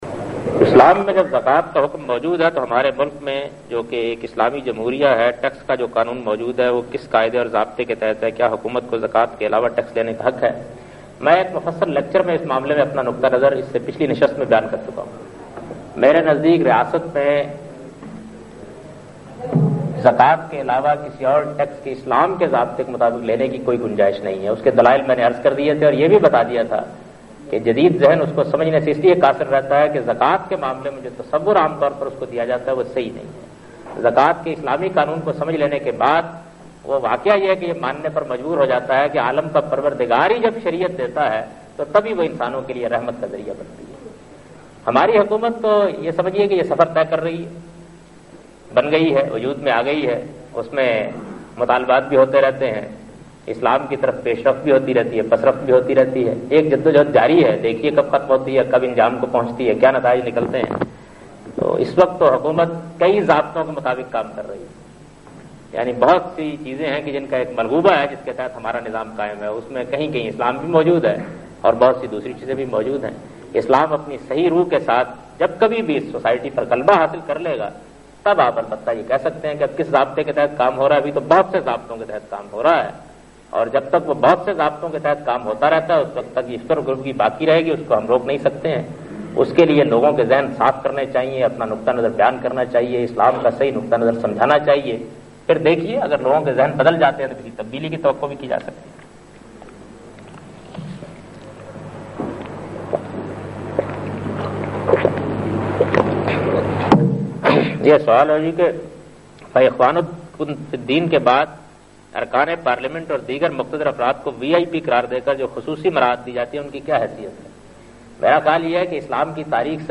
Lecture by Javed Ahmad Ghamidi on the topic Zakah and Islamic Law followed by Question Answer Session.